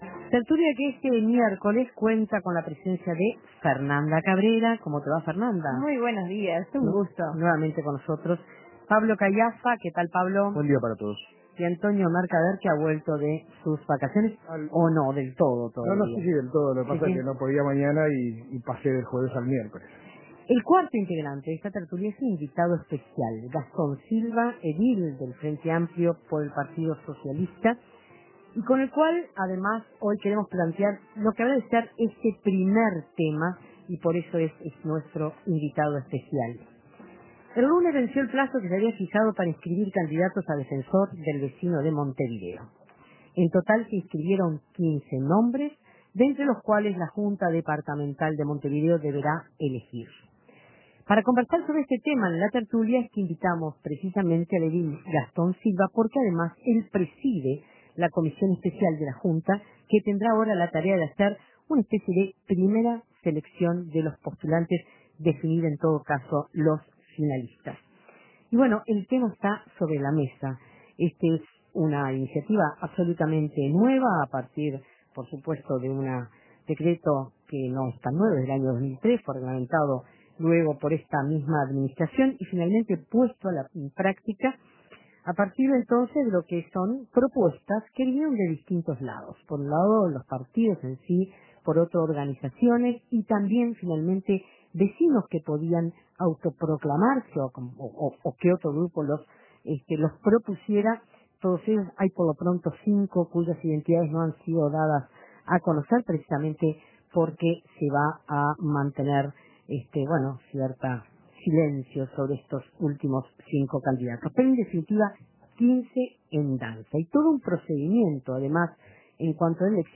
Quince candidatos disputan convertirse en el ombudsman de Montevideo (Con el edil Gastón Silva, presidente de la Comisión que deberá realizar la primera selección)